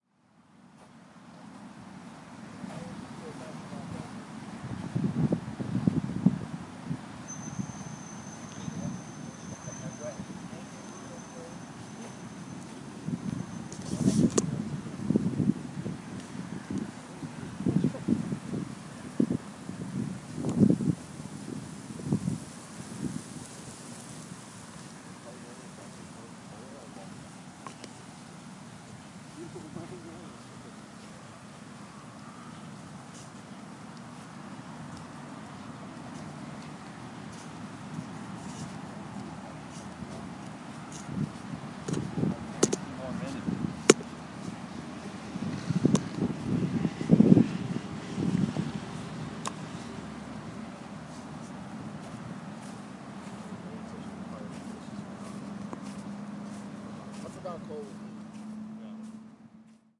健步走 " 健步走足球场
可能会听到洒水车，汽车驾驶和其他城市声音。
Tag: soundwalk 现场记录 洒水车 汽车